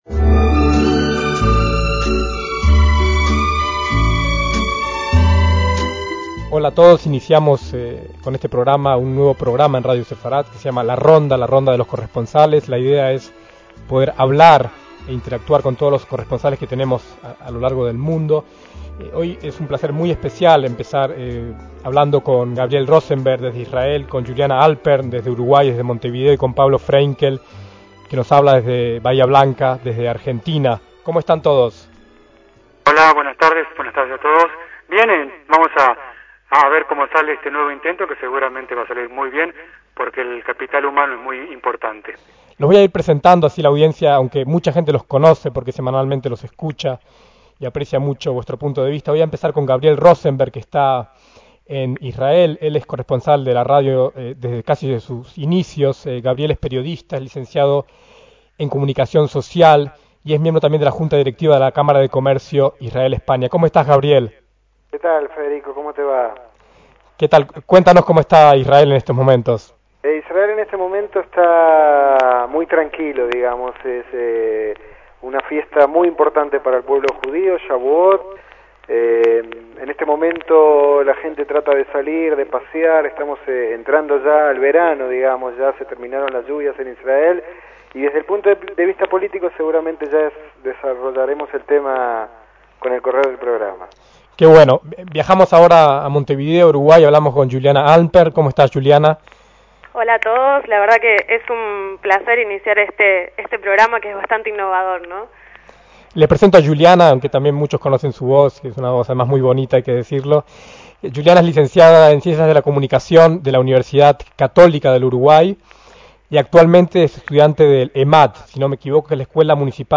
En junio de 2006 comenzaba un nuevo formato, entonces novedoso, de programa de debate telemático a varias bandas